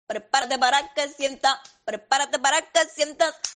Los sonidos del maquinillo